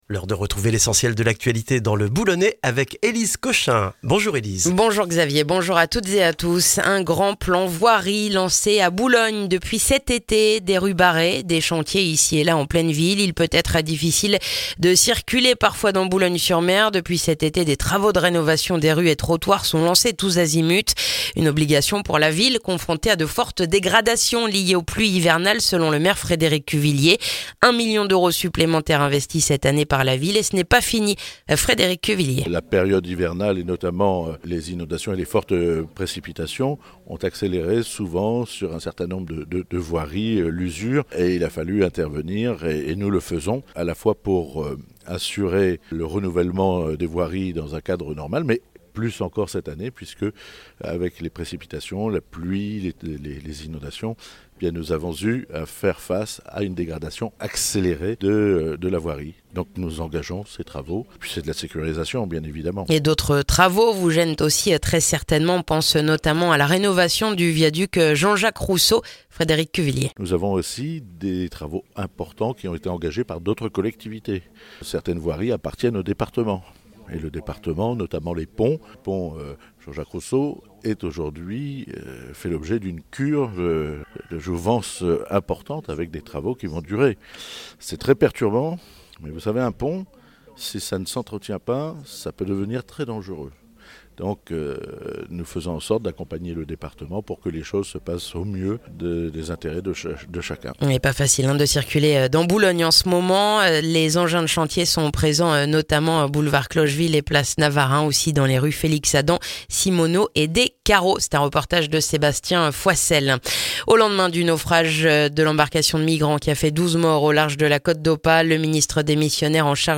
Le journal du jeudi 5 septembre dans le boulonnais